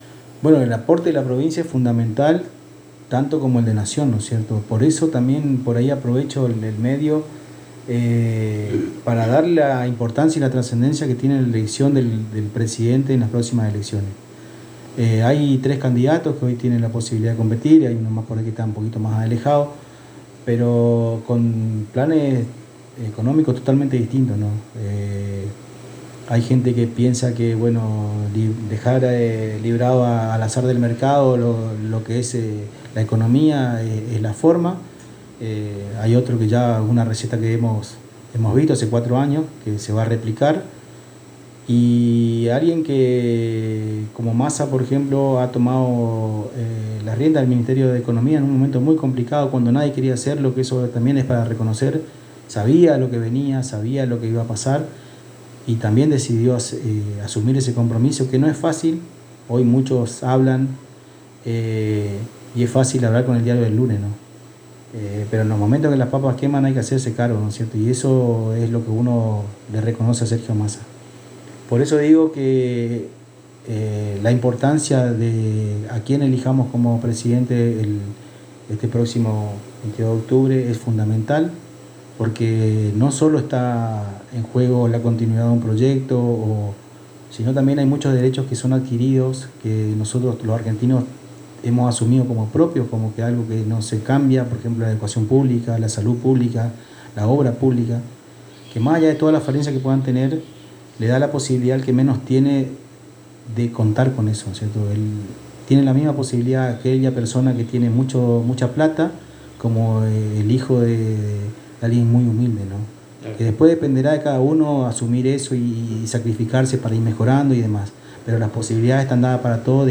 En diálogo exclusivo con la ANG el Secretario de Obras Públicas de Apóstoles Juan José Ferreyra comentó las obras de arreglo de caminos en los distintos barrios de Apóstoles trabajos de empedrado, cordón cuneta, apertura de calles, alumbrado, canalizaciones etc.